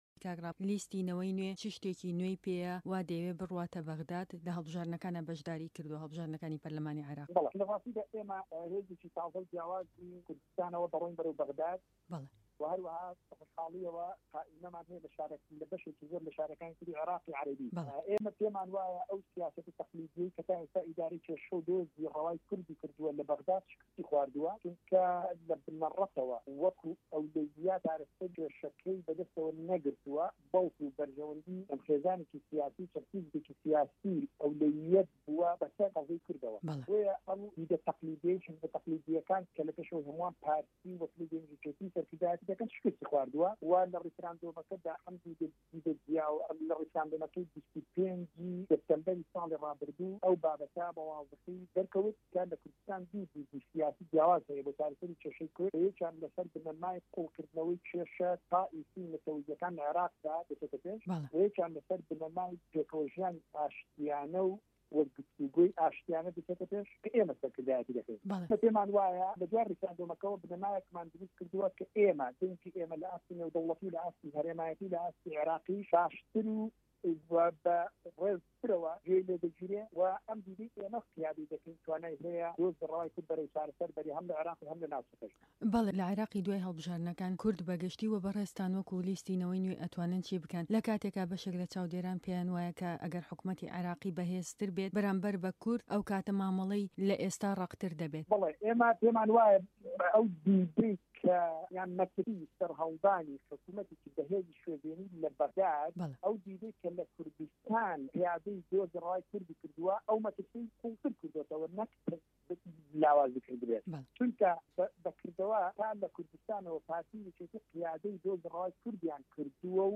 ده‌قی وتووێژه‌كه‌ی به‌شی كوردی ده‌نگی ئه‌مریكا له‌گه‌ڵ ڕابوون مه‌عروف